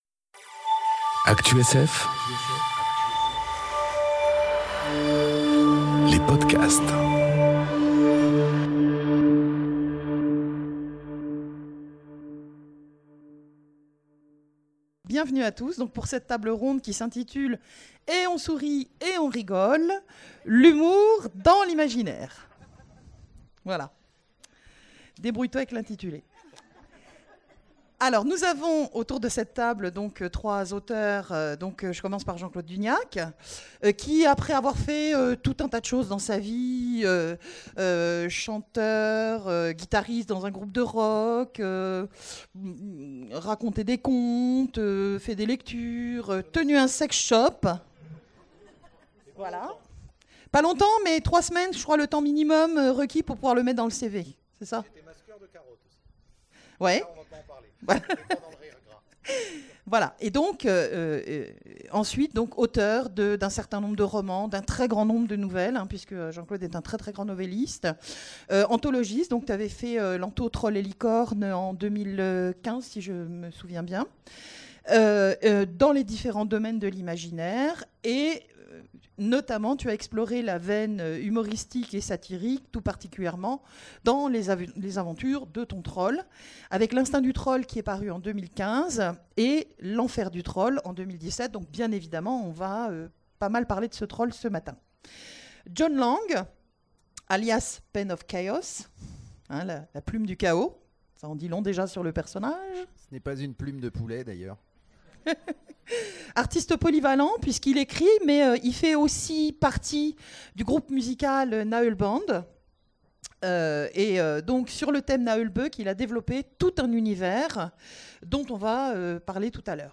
Conférence Et on sourit, et on rigole ! Humour et imaginaire... enregistrée aux Imaginales 2018